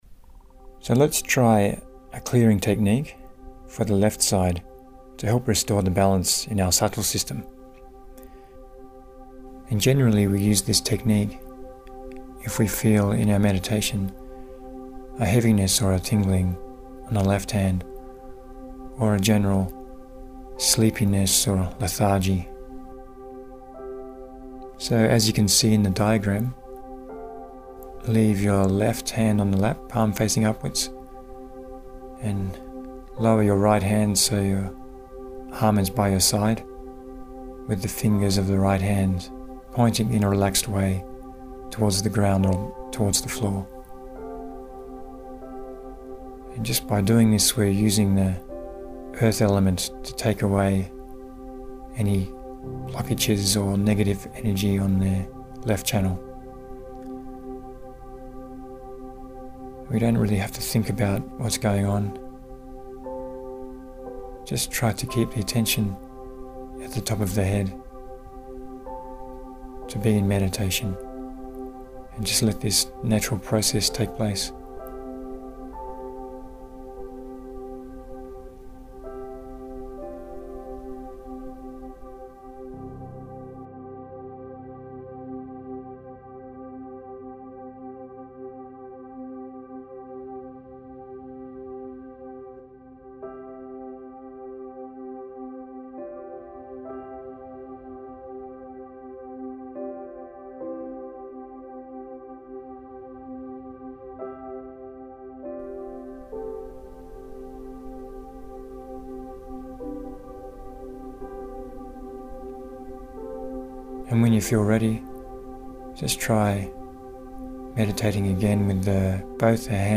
Audio guide for clearing Left Side (3mins:10s)